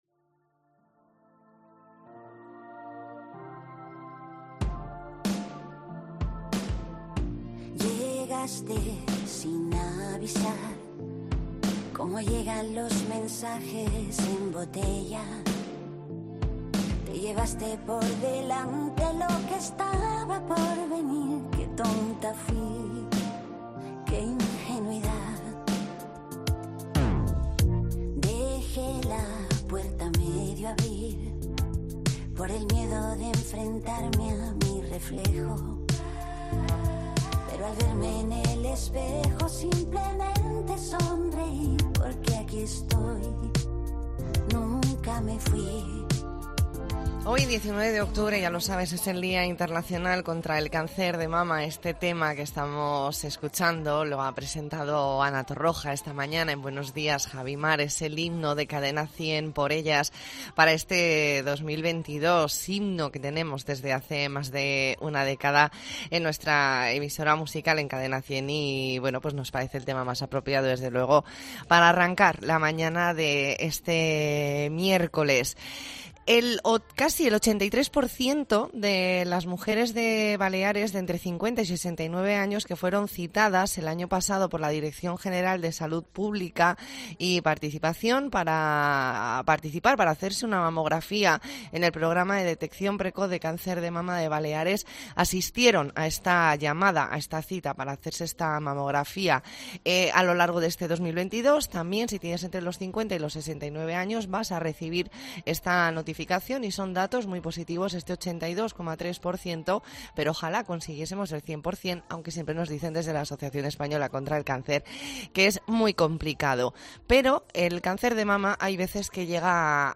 Entrevista en La Mañana en COPE Más Mallorca, miércoles 19 de octubre de 2022.